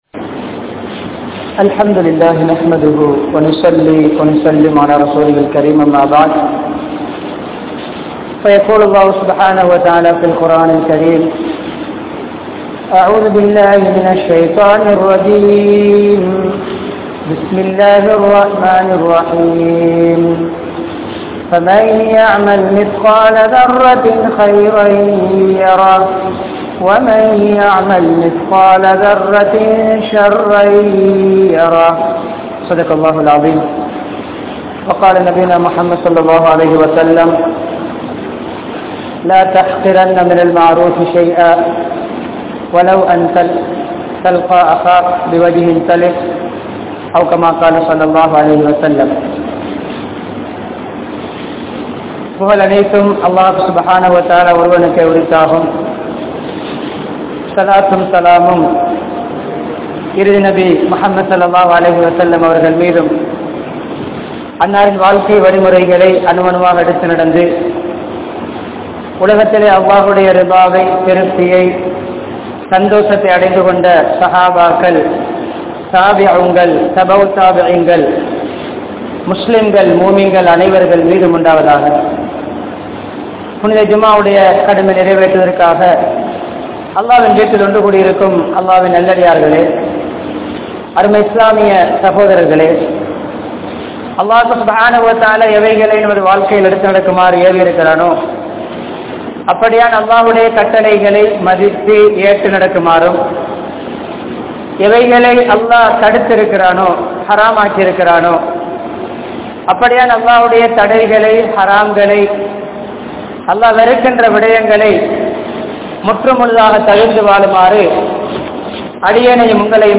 Ramalaanai Evvaaru Kalippathu? (ரமழானை எவ்வாறு கழிப்பது?) | Audio Bayans | All Ceylon Muslim Youth Community | Addalaichenai
Colombo, Kolannawa, Masjithur Rahma